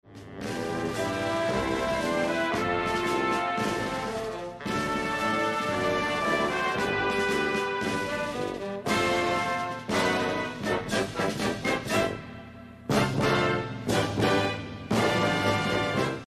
PLAY Musica Tradicional CATALUNHA